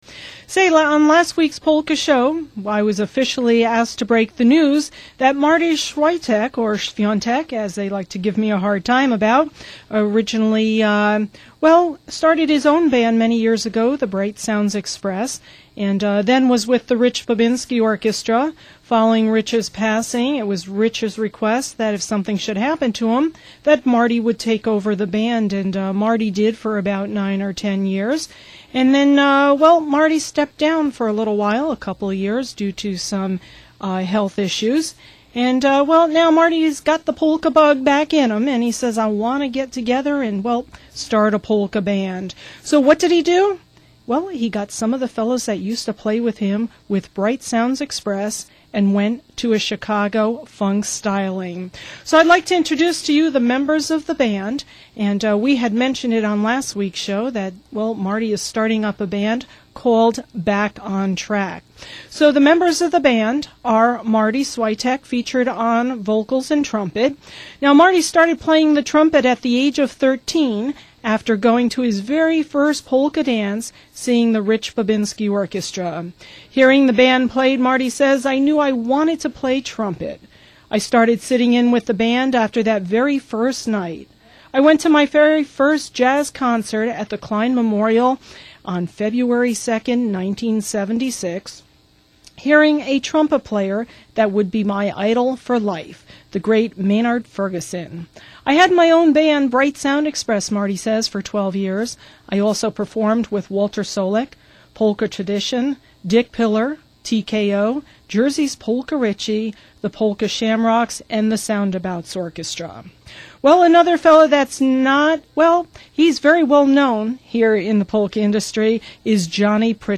It's a compiled segement of what went out over the air....
Quality is pretty good for a direct capture off her stream... and the cut is just a demo...